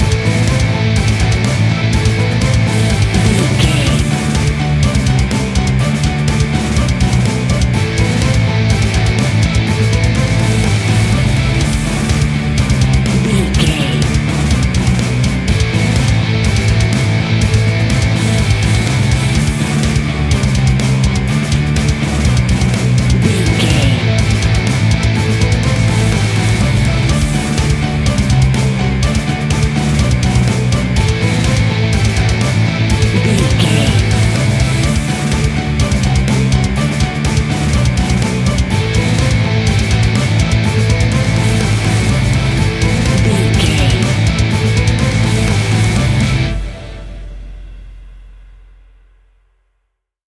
Epic / Action
Fast paced
Aeolian/Minor
Fast
hard rock
guitars
instrumentals
Heavy Metal Guitars
Metal Drums
Heavy Bass Guitars